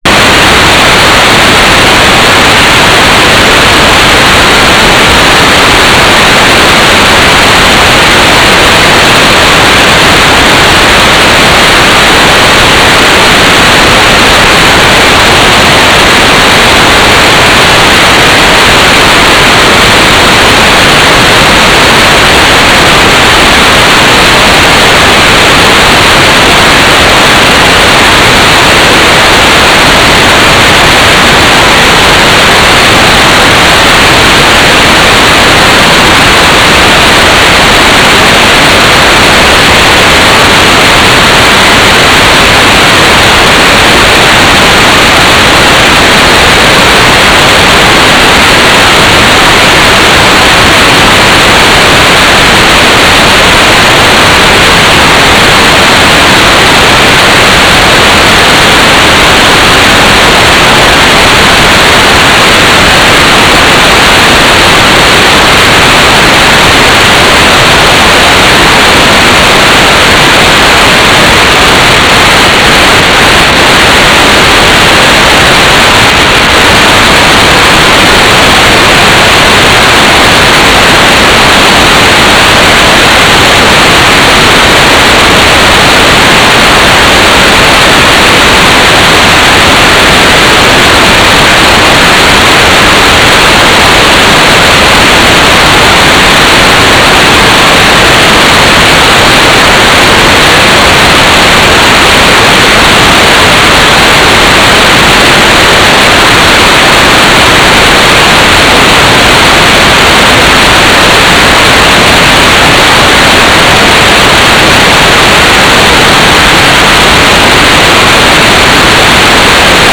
"station_name": "Sternwarte Sonneberg Turnstile VHF (Test UHF)",
"transmitter_description": "Mode U - GFSK4k8 - AX.25 - Telemetry",